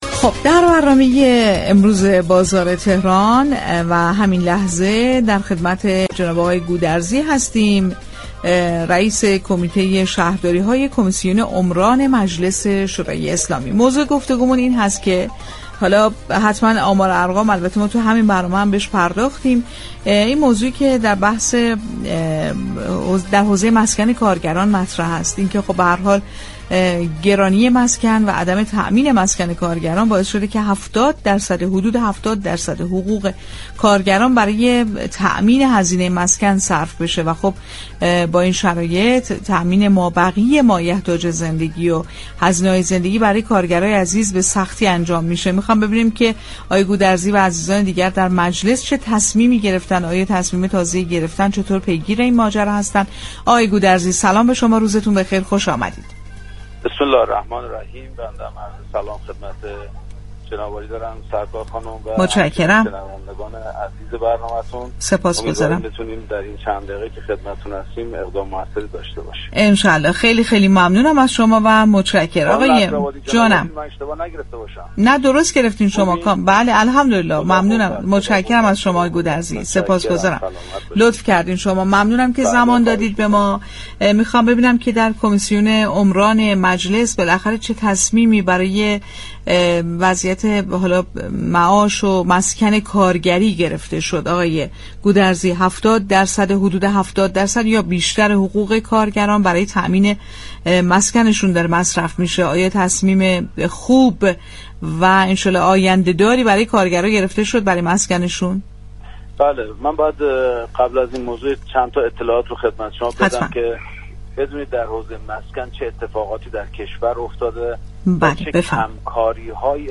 رییس كمیته شهرداری‌های كمیسیون عمران مجلس شورای اسلامی در گفتگو با رادیو تهران اظهار داشت: امروز حدود 70 درصد حقوق كارگران صرف هزینه مسكن می‌شود؛ ما در كمیسیون عمران ورود كرده‌ایم و امیدواریم با وام‌های حمایتی و نهضت ملی مسكن، بخشی از این فشار را كاهش دهیم.